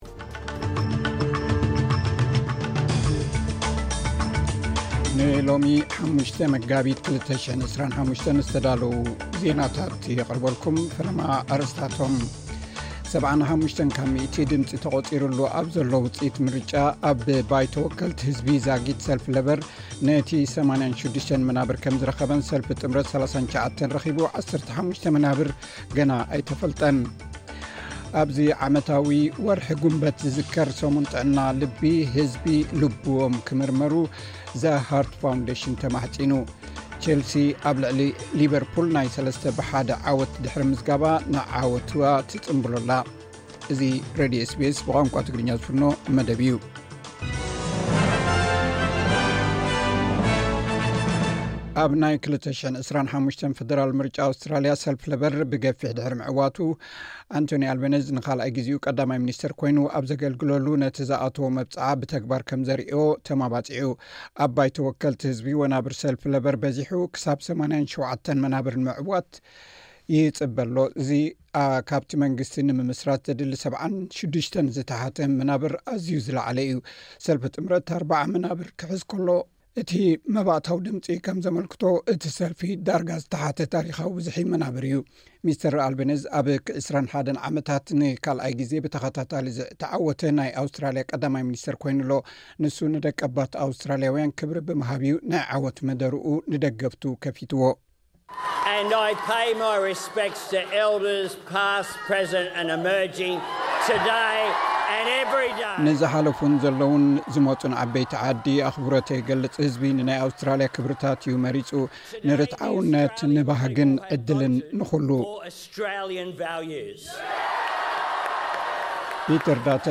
ዕለታዊ ዜና ኤስ ቢ ኤስ ትግርኛ (05 ግንቦት 2025)